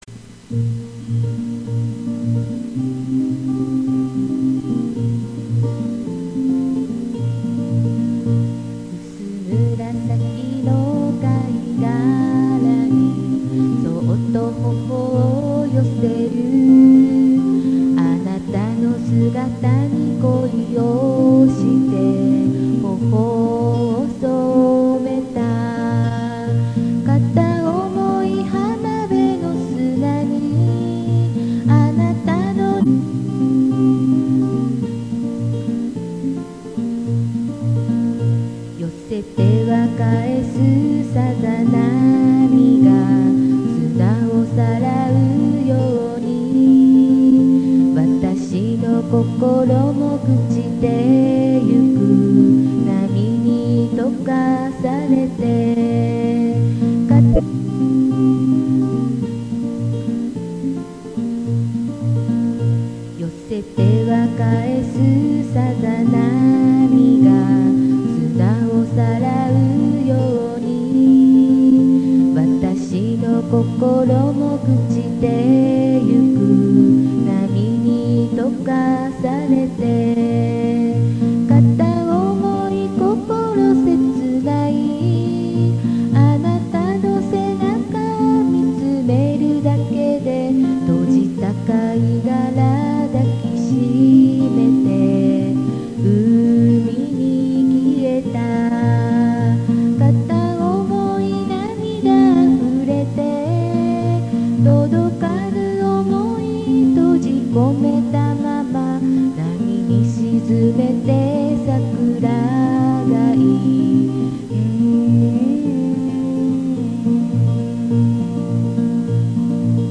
音質は悪いですが、聞いてみて下さい。
サウンドレコーダーに入れると、音質が下がります。
録音してみたら昔のフォーク風で、失恋の詩なのに明るい曲になりました。